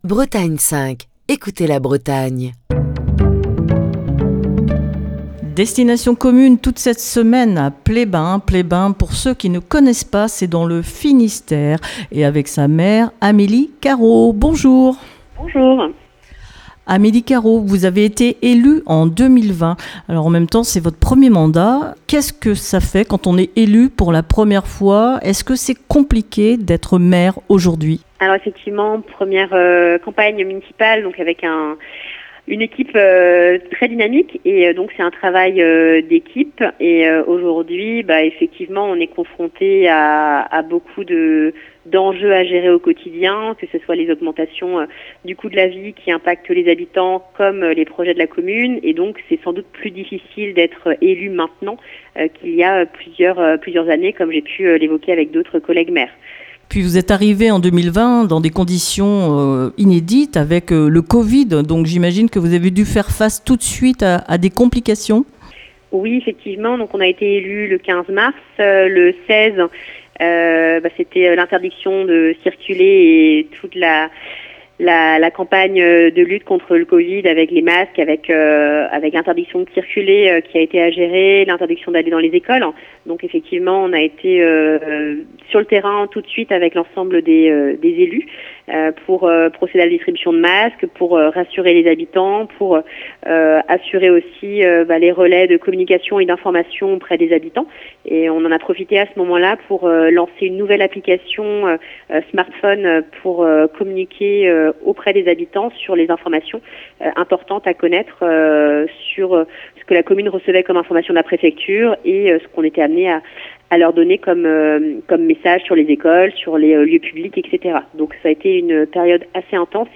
Cette semaine, Destination commune pose ses micros dans le Finistère à Pleyben.